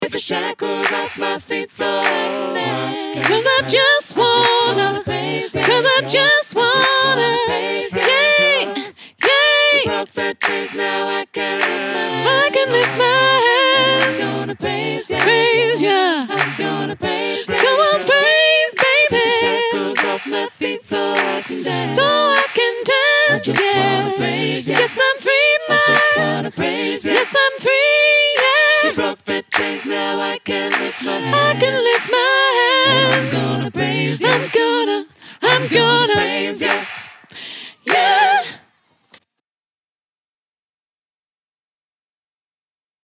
Shackles – sop solo – last 2 choruses